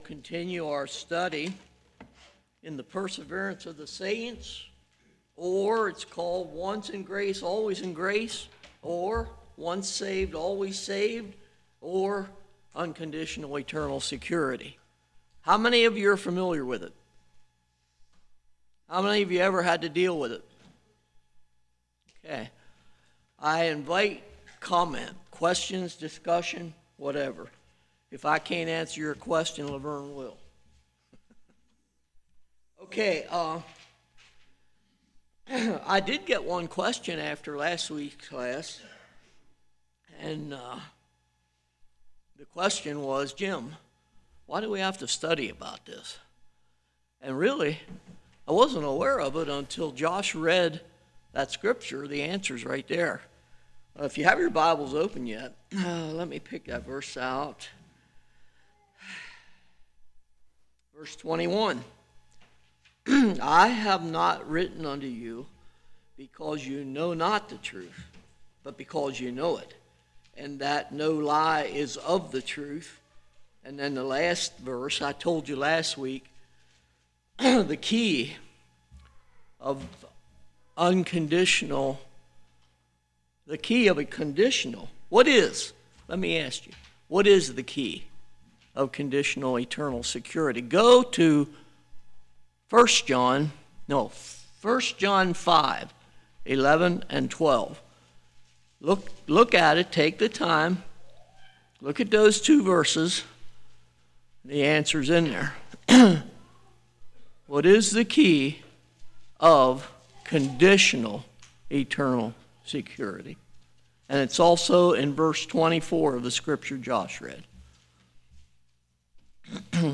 1 John 2:18-24 Service Type: Winter Bible Study Question of works Can salvation be forfeited?